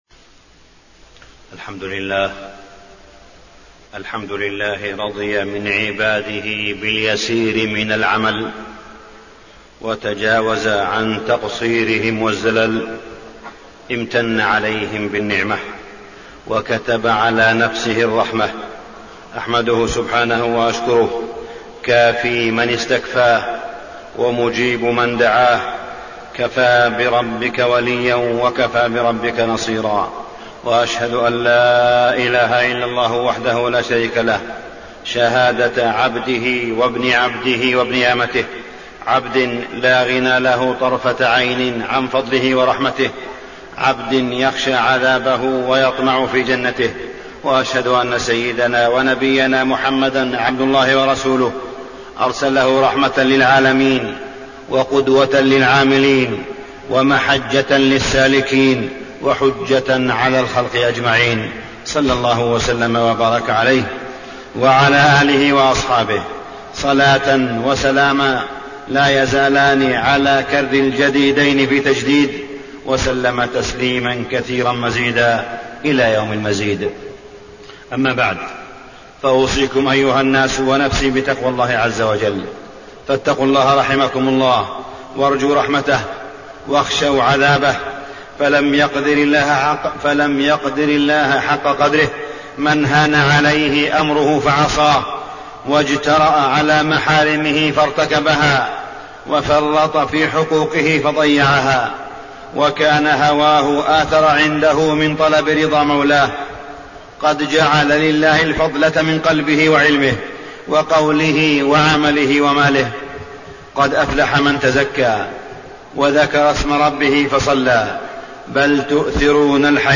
تاريخ النشر ٢٥ جمادى الأولى ١٤٢٩ هـ المكان: المسجد الحرام الشيخ: معالي الشيخ أ.د. صالح بن عبدالله بن حميد معالي الشيخ أ.د. صالح بن عبدالله بن حميد المحاسبة والتقويم